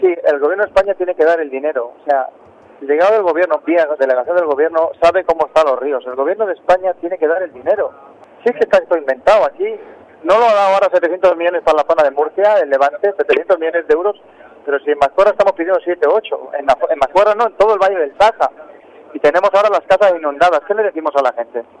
Y el alcalde de Mazcuerras, Celestino Fernández, también en ARCO FM, ha responsabilizado al Gobierno de España. Por una parte por no entregar todavía el dinero de las ayudas de las inundaciones de enero, en comparación con los fondos enviados a Murcia por la gota fría. Y por otro lado, ha recordado que han pedido la construcción de una escollera a la Confederación Hidrográfica del Cantábrico.